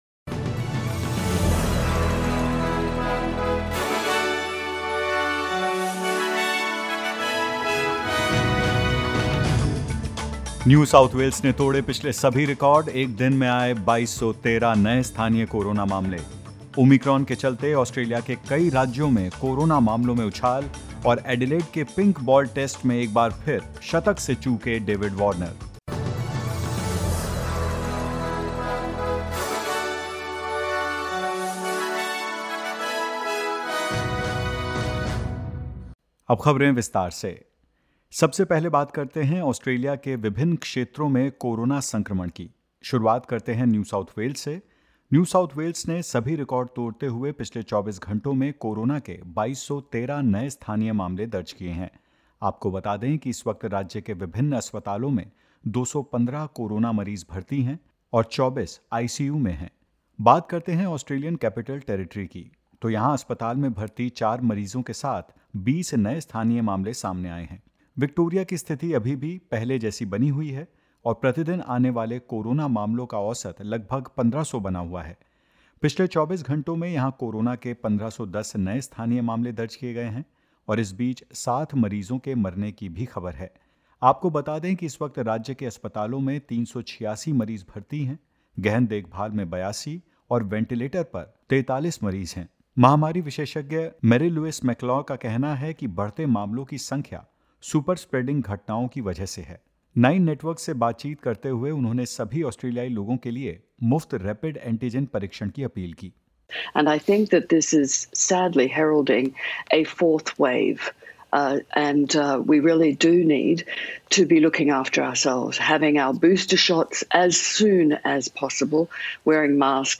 In this latest SBS Hindi news bulletin: The Queensland government mandates face masks in essential retailers and on public transport; In the second test of the Ashes, Australia is all set to score a big first inning score against England and more.